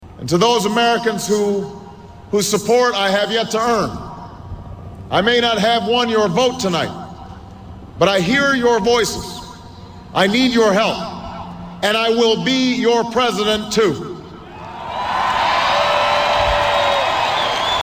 Tags: Barack Obama Barack Obama speech Barack Obama clips US President History